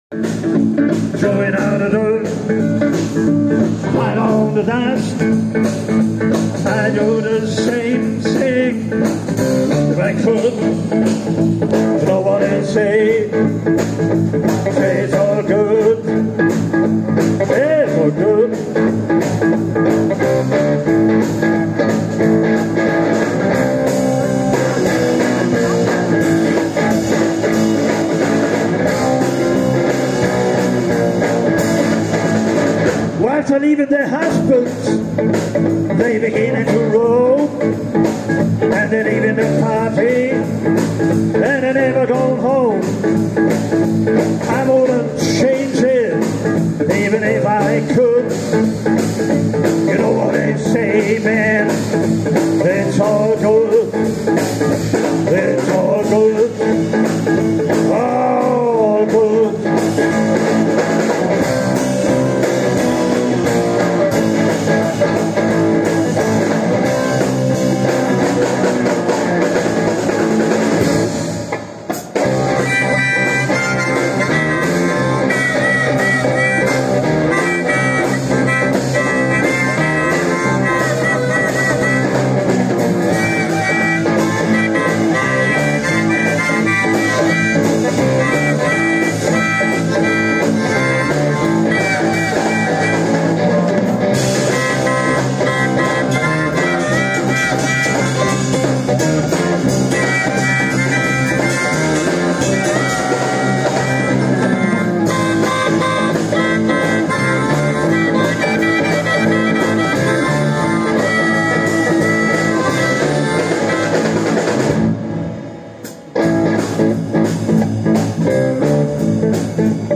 (14.07.2024) Zwei Gigs gegen Deutschland.
Smartphone hört mit: